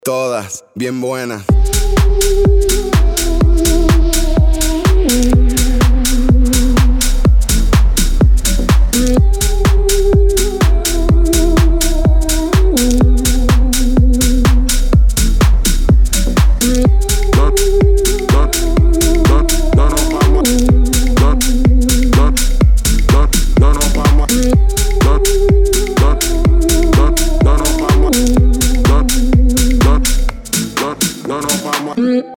Испанская грустная